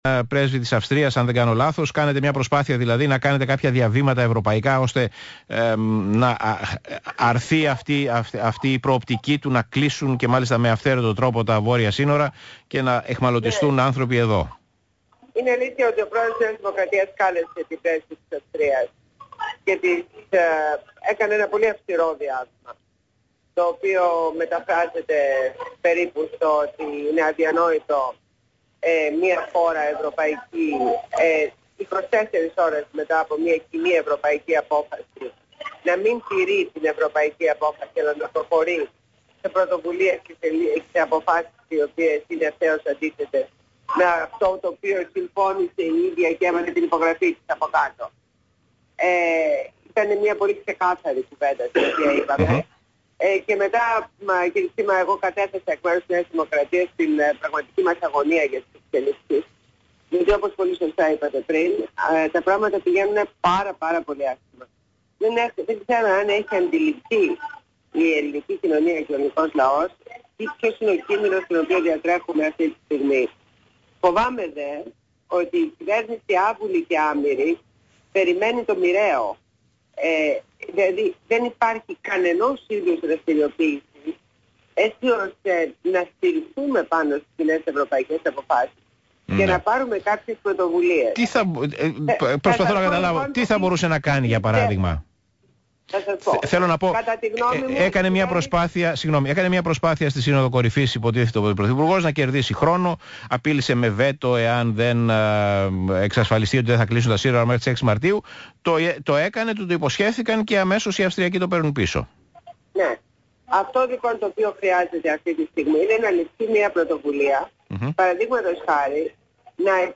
Συνέντευξη στο ραδιόφωνο του ΣΚΑΙ στην εκπομπή του δημοσιογράφου Π. Τσίμα.